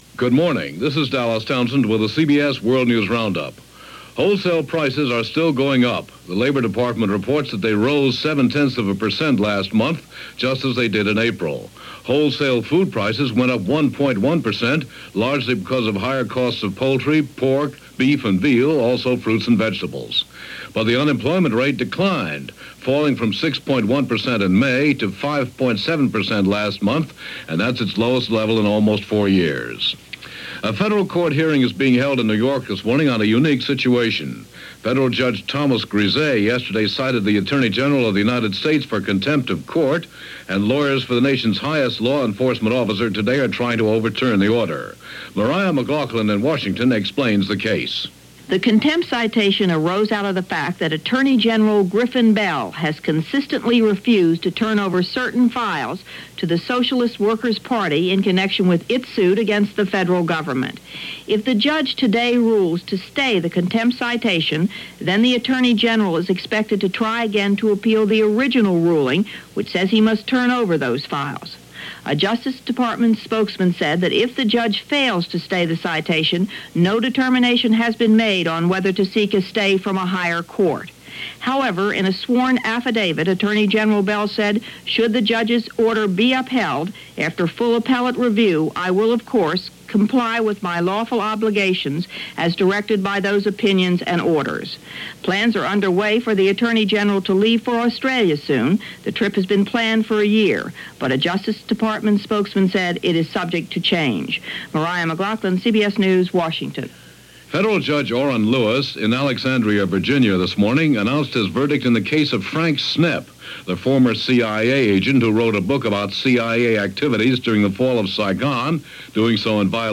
All that, and a whole lot more for this July 7, 1978 as presented by The CBS World News Roundup.